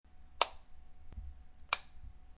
Light switch
light-switch-79910.mp3